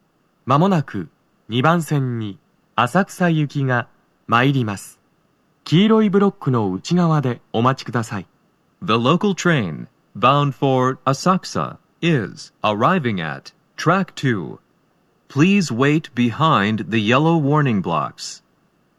スピーカー種類 TOA天井型()
🎤おススメ収録場所 1番線…最前部付近スピーカー/2番線…最前部付近スピーカー
鳴動は、やや遅めです。
1番線 渋谷方面 接近放送 【女声